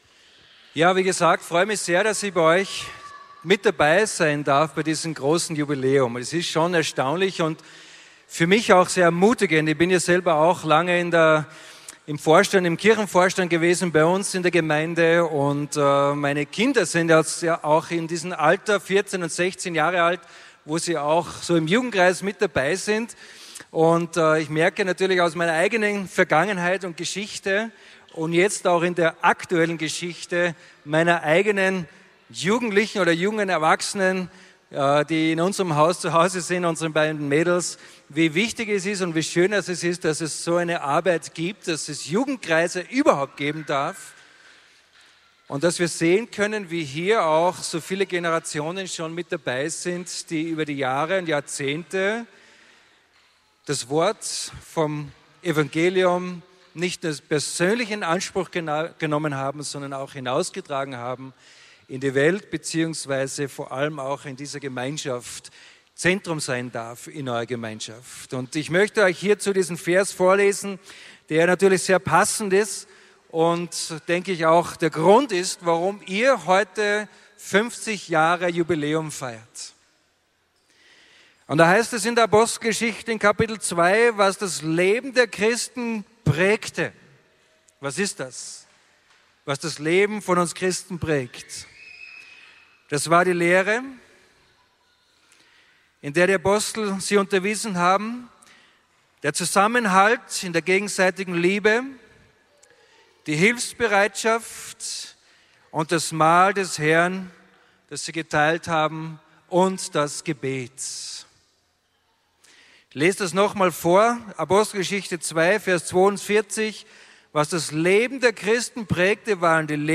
Impuls